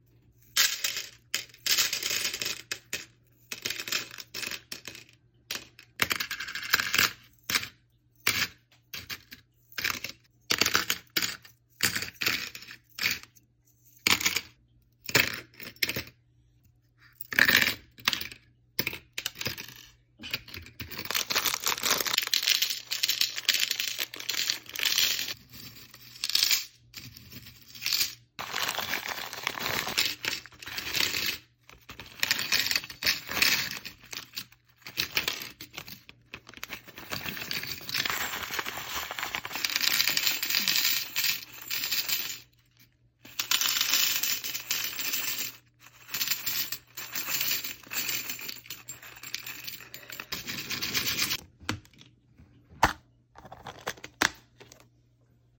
tried out this ASMR thing sound effects free download